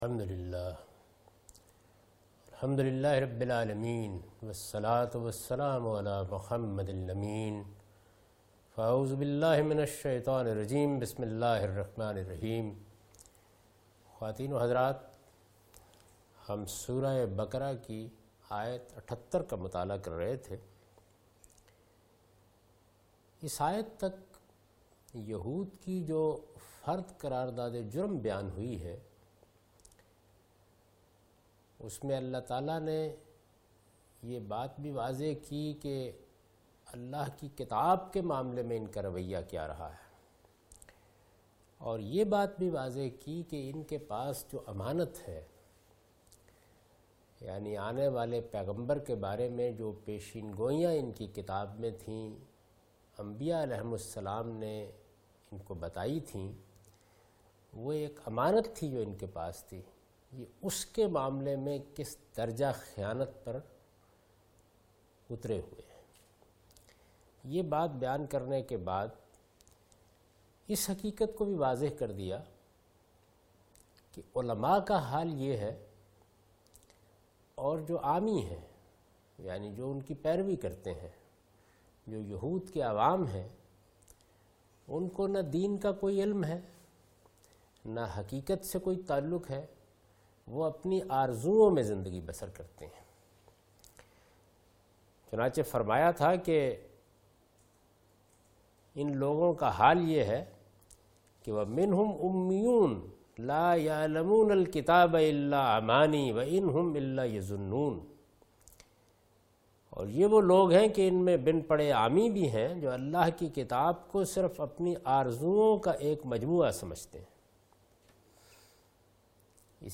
Surah Al-Baqarah - A lecture of Tafseer-ul-Quran – Al-Bayan by Javed Ahmad Ghamidi. Commentary and explanation of verse 78,79,80,81,82 and 83 (Lecture recorded on 10th Oct 2013).